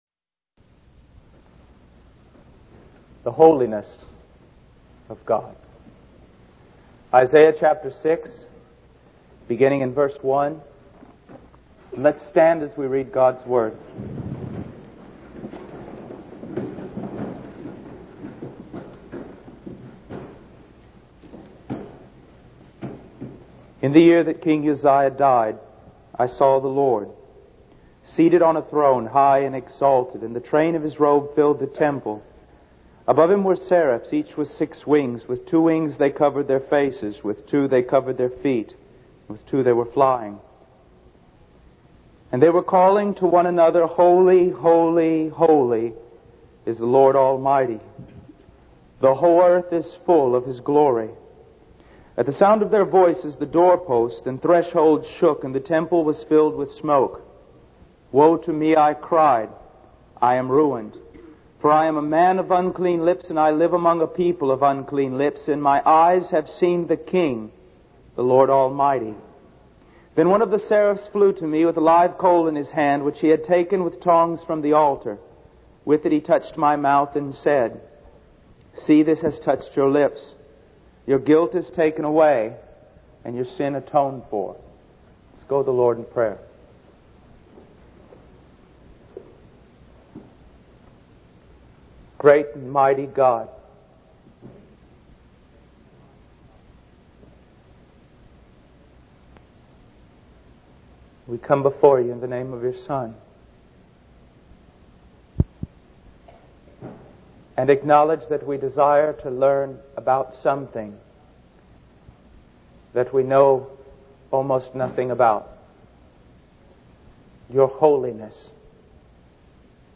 In this sermon, the preacher emphasizes the importance of recognizing the privilege and honor it is to be in the presence of God. He uses the example of Moses encountering God in the burning bush and how Moses humbled himself and took off his shoes because he was standing on holy ground.